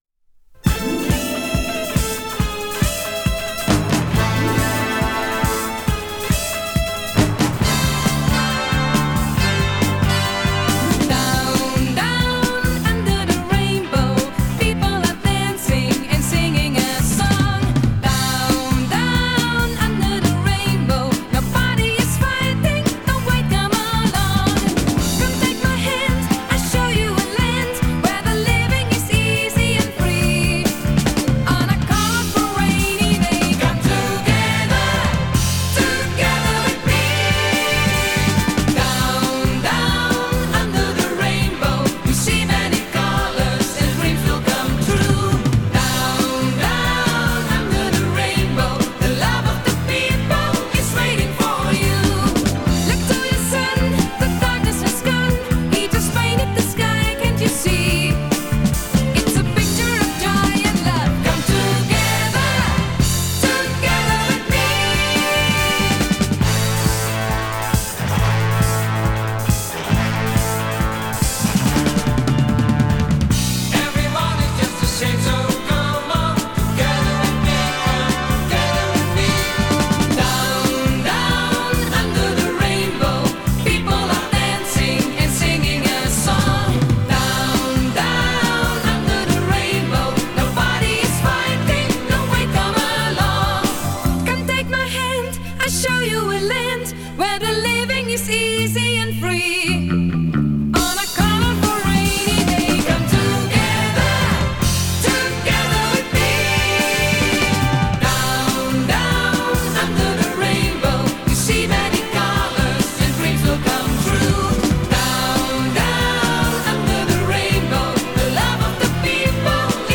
Жанр: Electronic, Rock, Funk / Soul, Pop
Recorded At – Dureco Studio